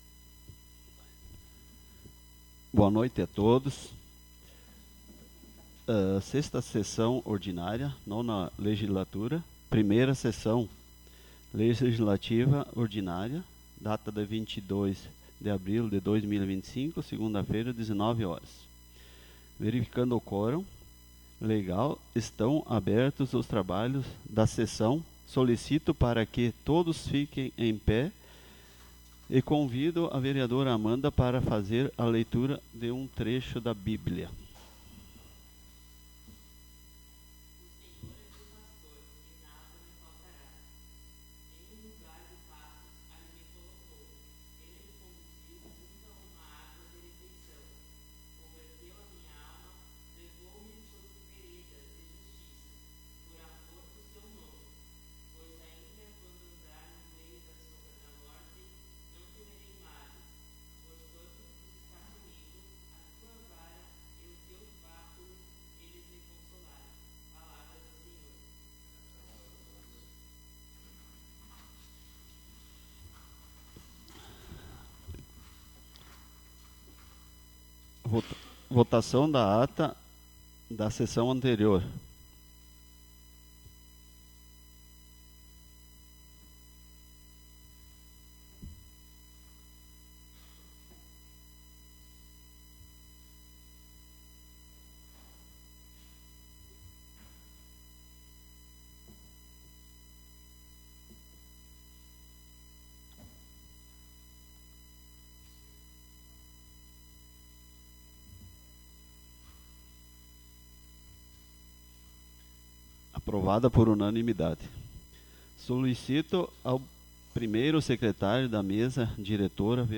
Audio 6ª Sessão 22.04.25 — Câmara Municipal